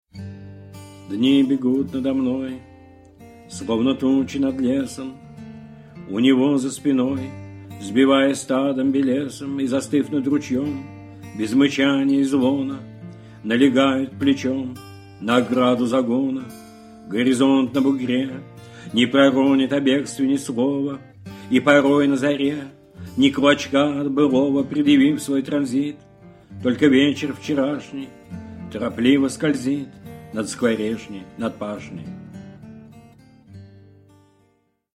iosif-brodskij-dni-begut-nado-mnoj-chitaet-avtor